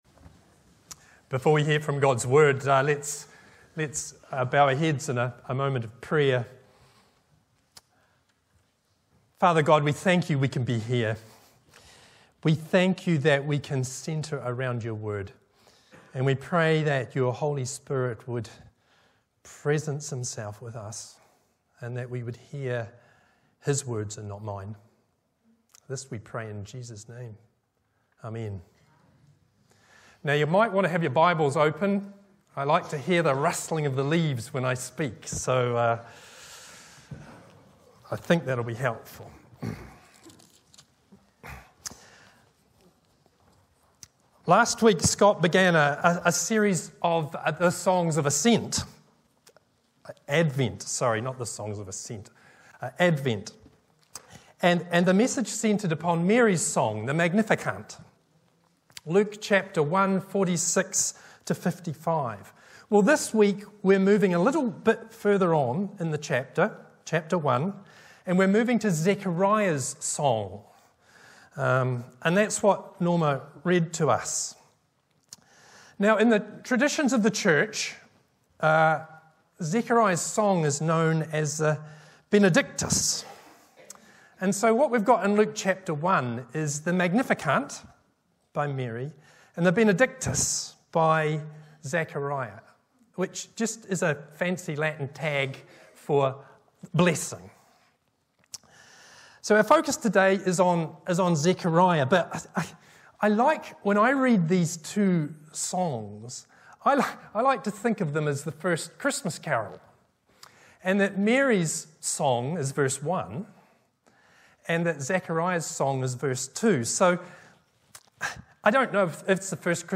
Dec 10, 2023 Zecahriah’s Song MP3 Subscribe to podcast Notes Sermons in this Series Preached on: Sunday 10th December 2023 The sermon text is available as subtitles in the Youtube video (the accuracy of which is not guaranteed).
Bible references: Luke 1:67-79 Location: Brightons Parish Church